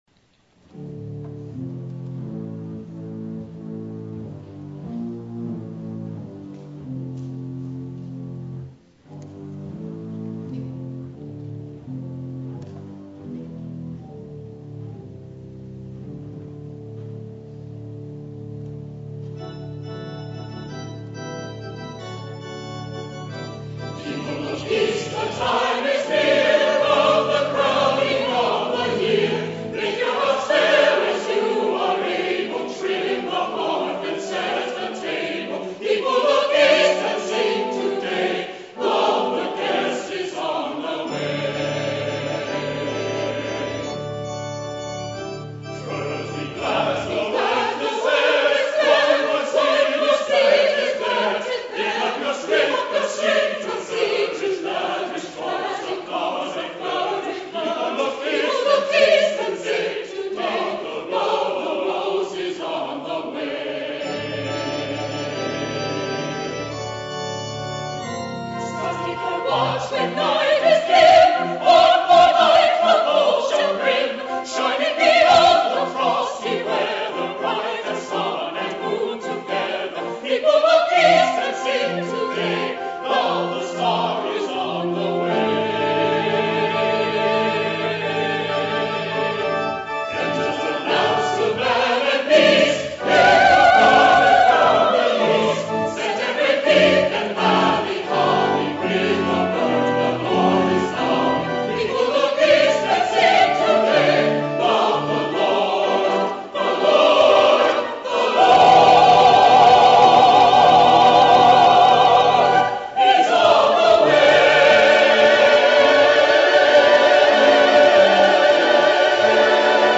The Second Reformed Chancel Choir sings "People Look East" by Eugene Butler
Christmas Concert 2012